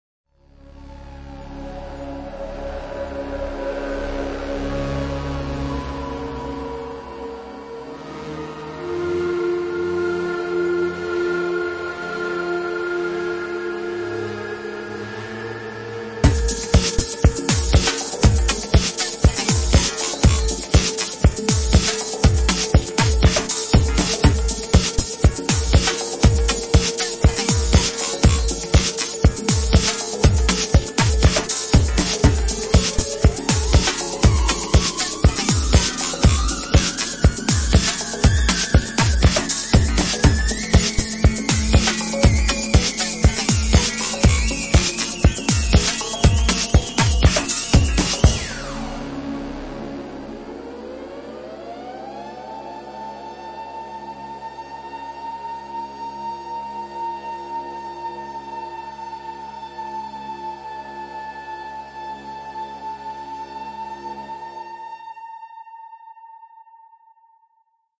诡异吉他音调
描述：用自己制作的吉他样品进行后期处理，折腾了一个奇怪的音调。
Tag: 吉他 波形 奇怪 朦胧